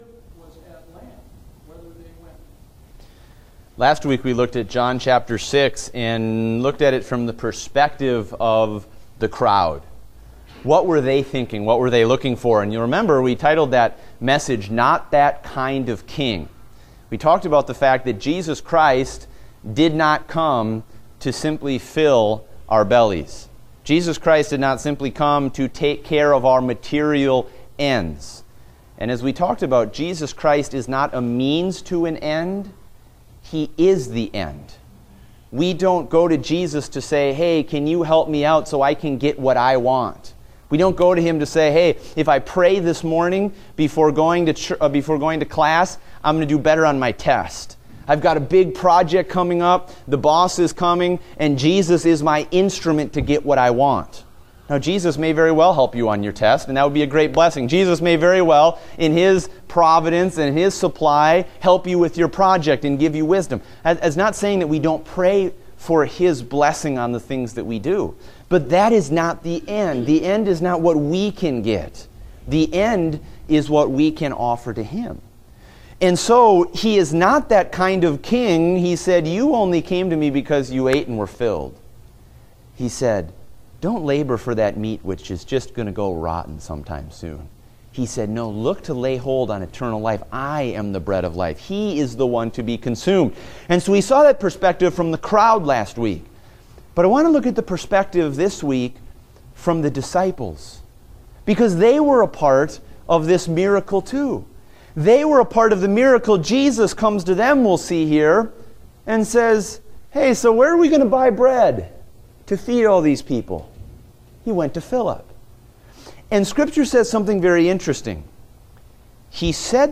Date: August 14, 2016 (Adult Sunday School)